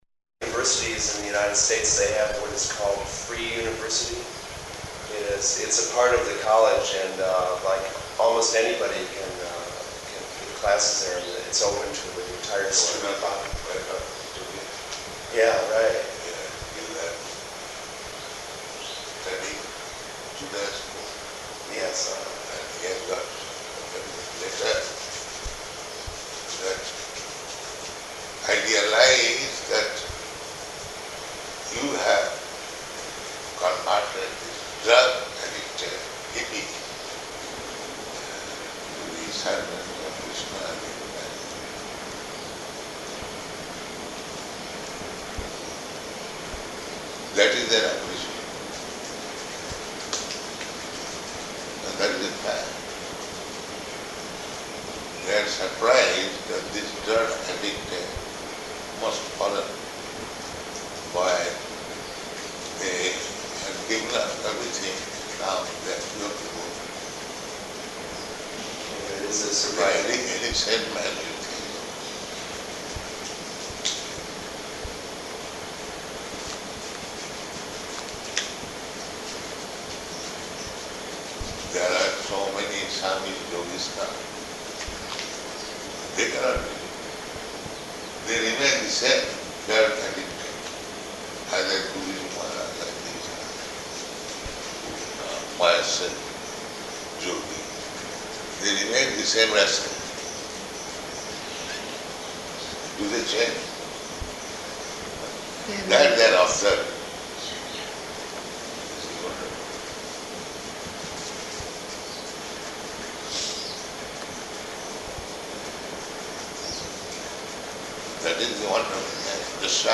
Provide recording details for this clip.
Location: Honolulu